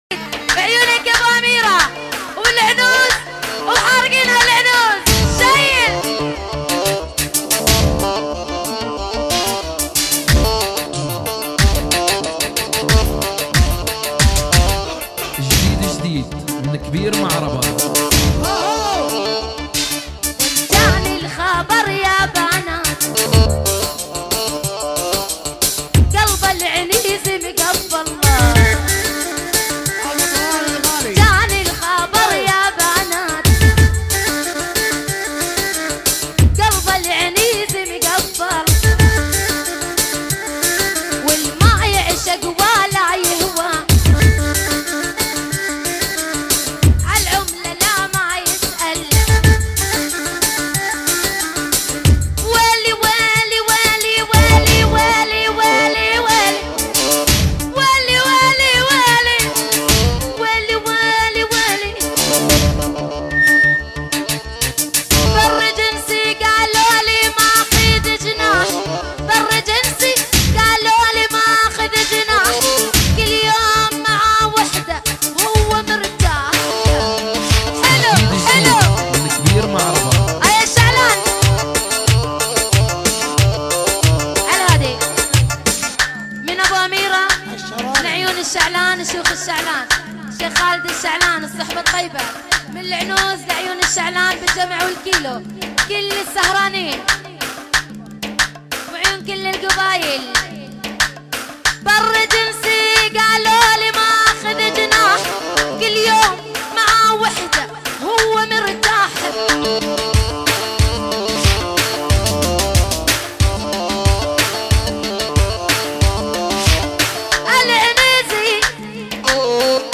دبكات